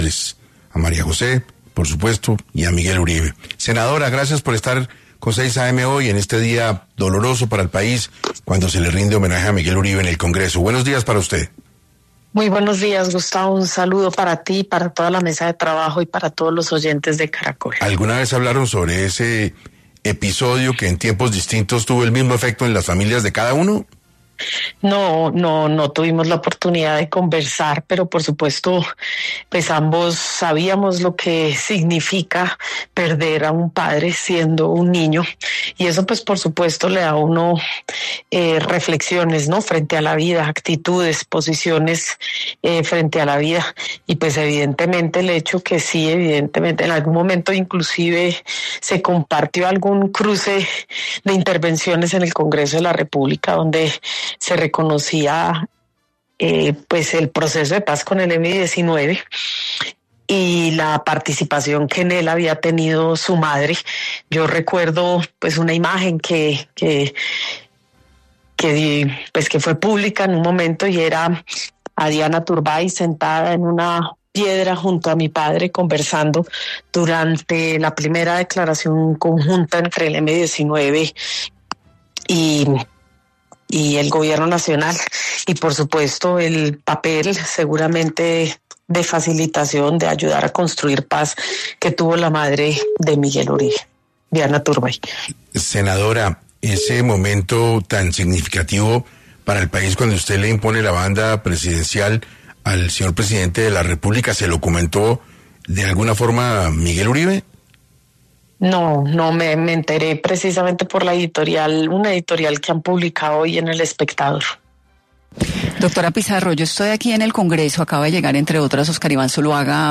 En diálogo con 6AM de Caracol Radio, Pizarro compartió sus reflexiones sobre el asesinato de Uribe Turbay y la importancia de la unidad en Colombia.